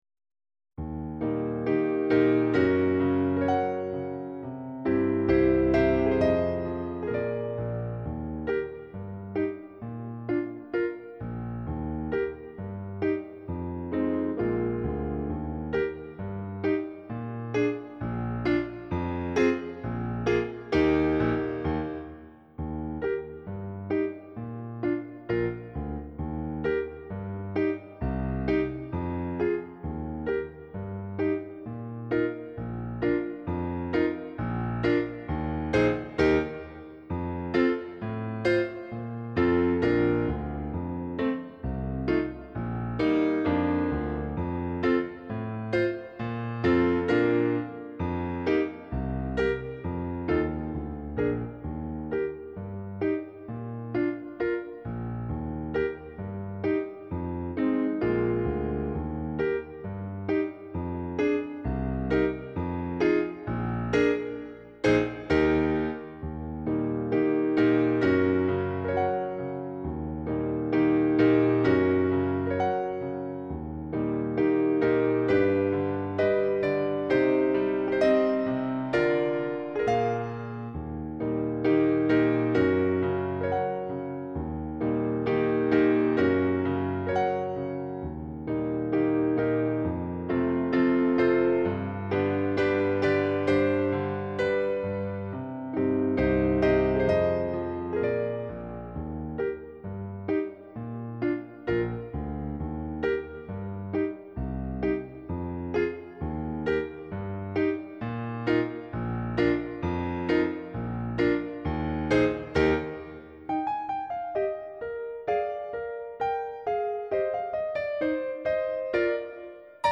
CD Play-along
(accomp. only, qn=132)